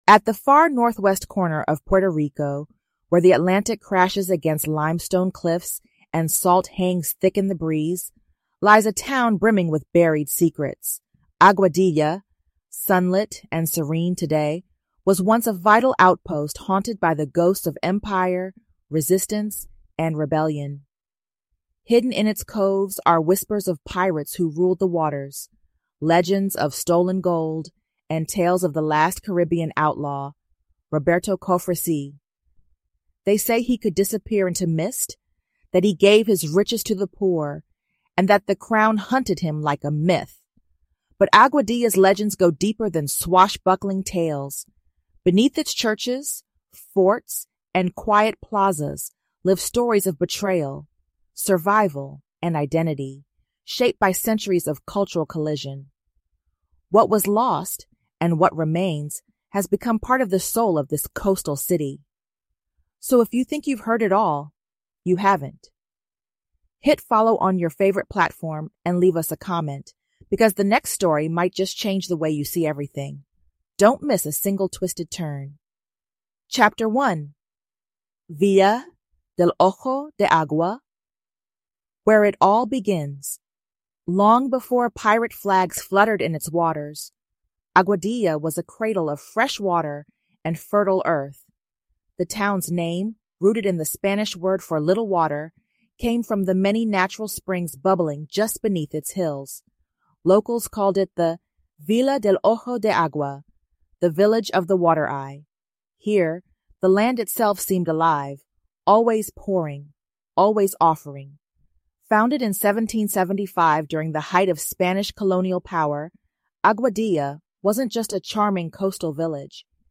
From Aguadilla’s ghostly coves to the echoing walls of Fuerte de la Concepción, this immersive audio experience weaves untold truths, Afro-Caribbean